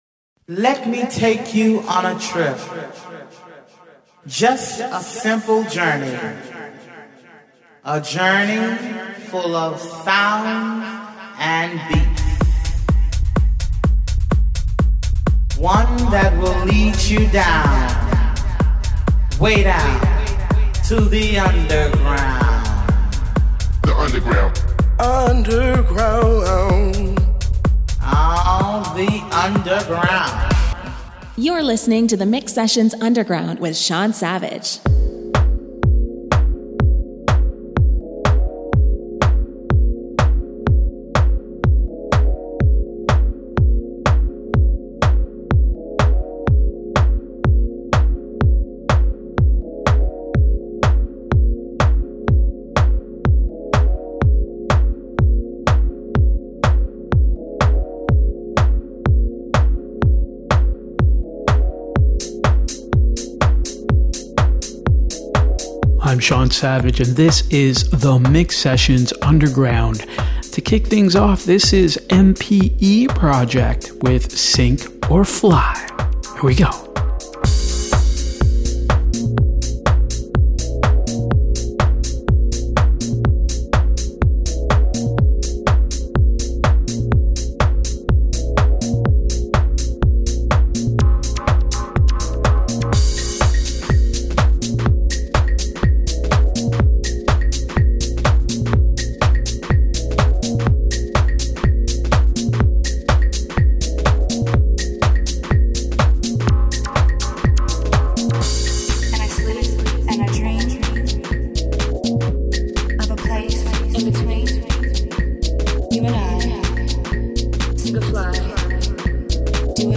Underground Deep House and Techno
Stereo